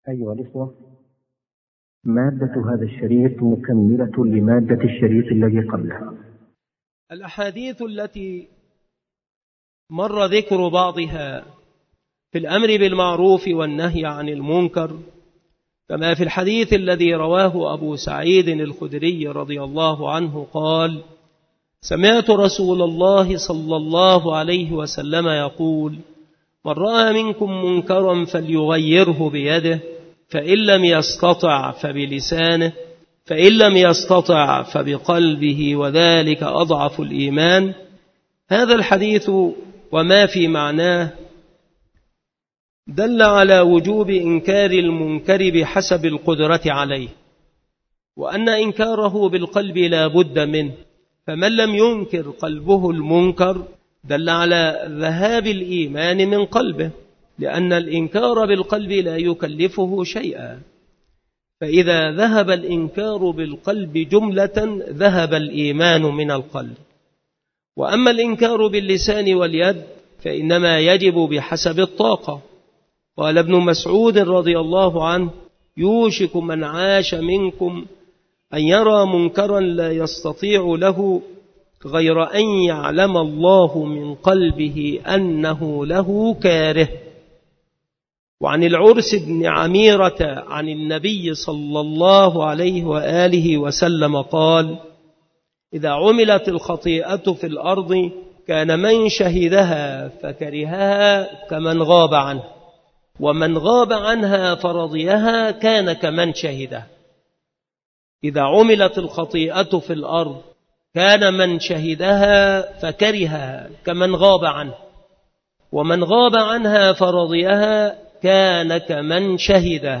التصنيف شروح الحديث
مكان إلقاء هذه المحاضرة بالمسجد الشرقي بسبك الأحد - أشمون - محافظة المنوفية - مصر